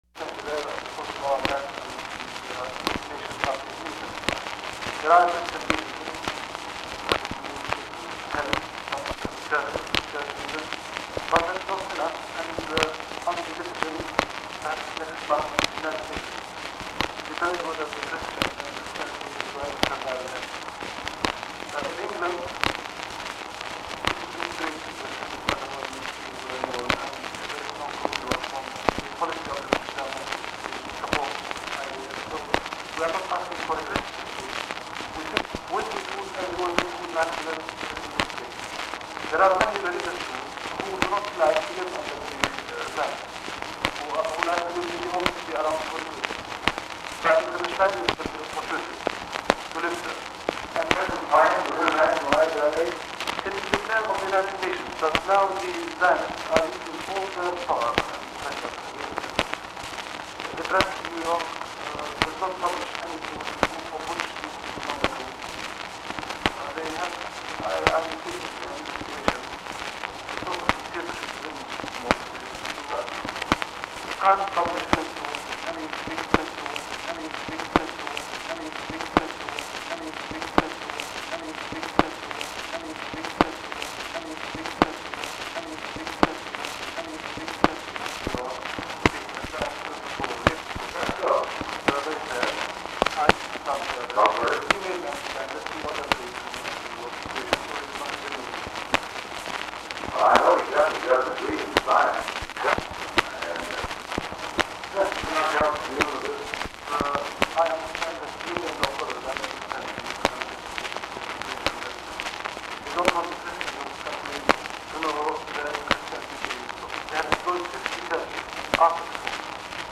The recording begins with the conversation already in progress.
Secret White House Tapes | Dwight D. Eisenhower Presidency